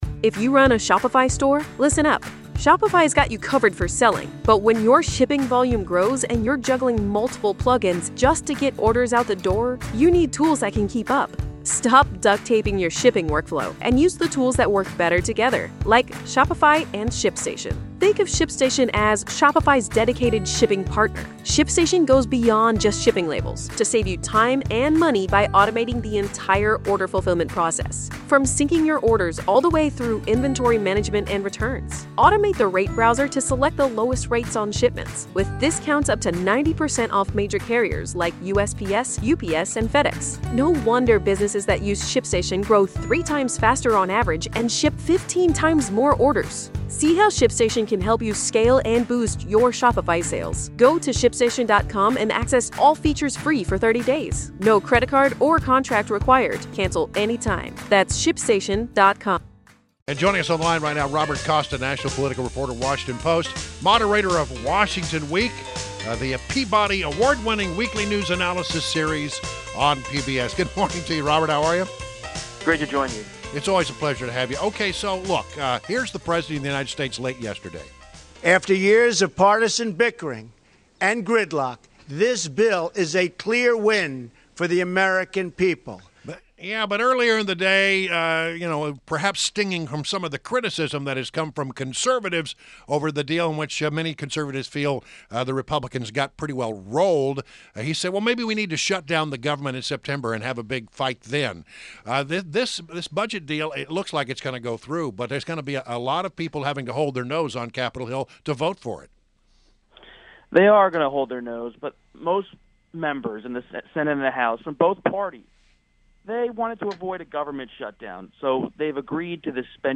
WMAL Interview - ROBERT COSTA - 05.03.17
INTERVIEW – ROBERT COSTA – National political reporter, Washington Post and Moderator of Washington Week, the weekly news analysis series on PBS – discussed the budget battle.